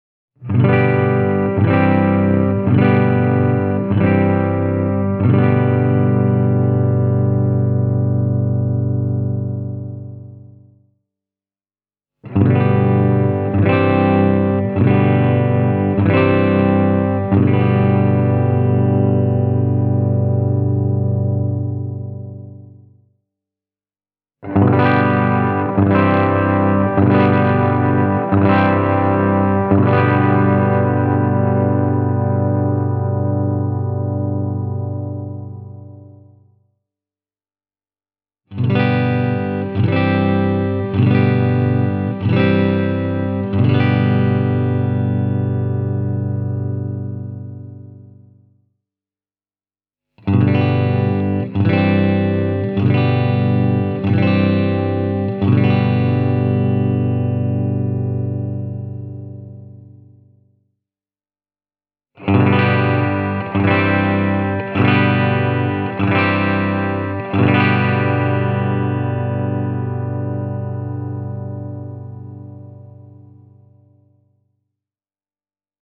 Fantomen reagoi mallikkaasti soittodynamiikkaan, ja se voi soida sekä pehmeän lempeästi että purevan hyökkäävästi.
Tässä esitetään kitaran pääsoundeja puhtailla vahvistinasetuksilla (käsintehty Bluetone Shadows Jr. -kombo). Täydet humbuckerit tulevat ensimmäisinä: